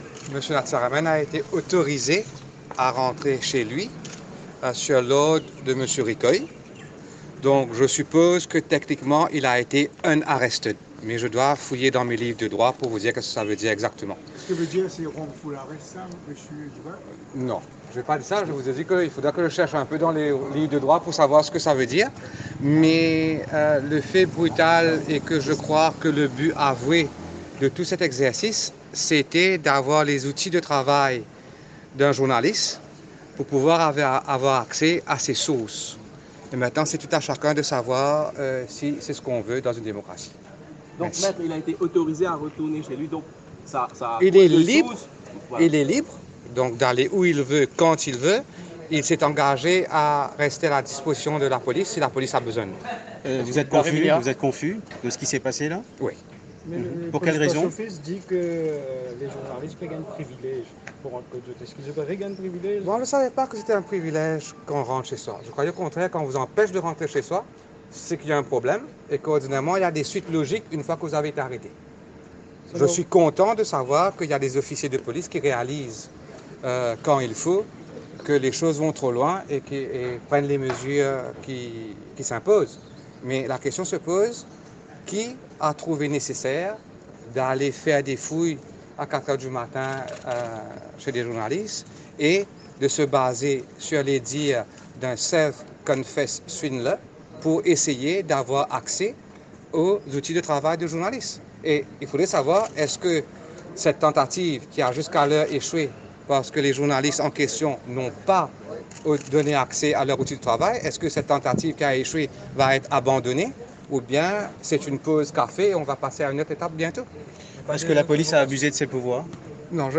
Déclaration